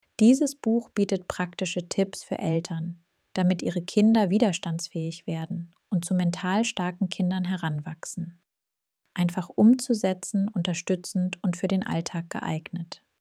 Hörbuchprobe deutsch
germang-sample-voice-clone-dJoP5WgOW5iPDXpP.mp3